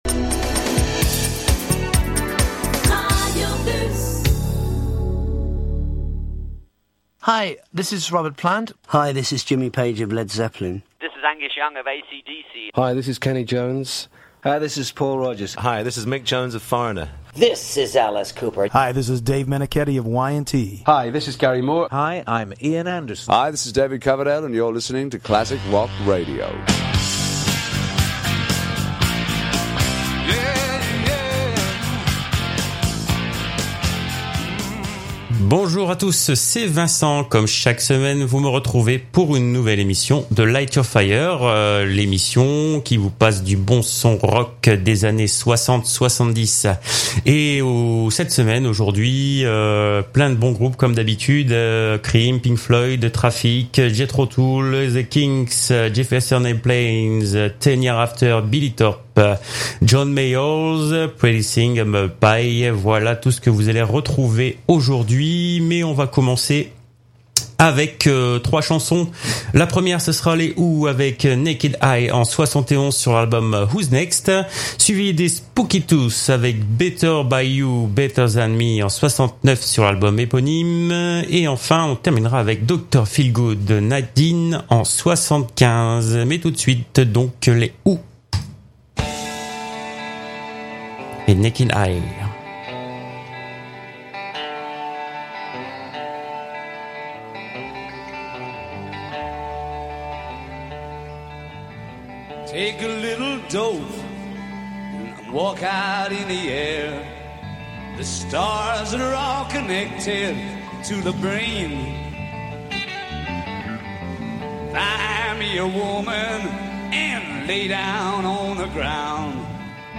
Réécoutez l’émission spéciale summer of love 1967 :entre guerre du vietnam et culture hippie, retrouvez les hits qui ont fait l’histoire d’un été pas comme les autres, entrecoupés d’extraits de film cultes retraçant cette période.